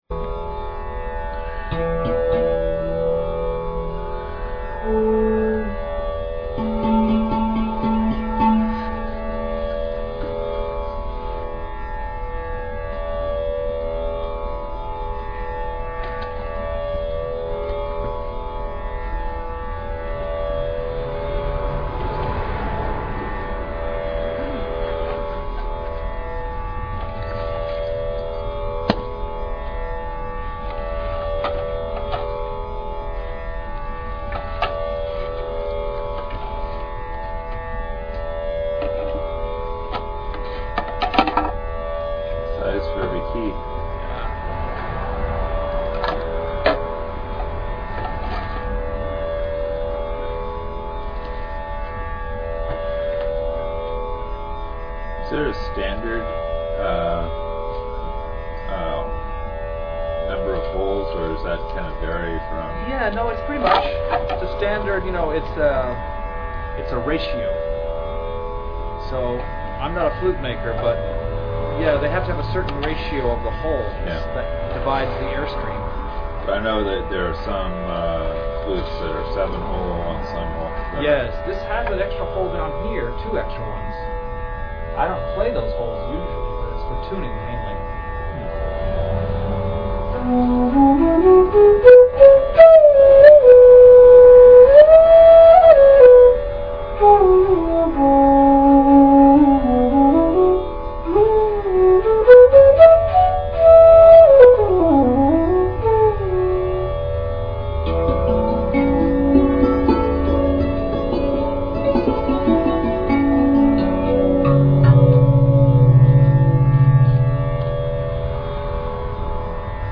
Archive of an event at Sonoma County's largest spiritual bookstore and premium loose leaf tea shop.
bansuri (North Indian bamboo flute)
traditional North Indian ragas
Get ready for a meditative and heart moving experience.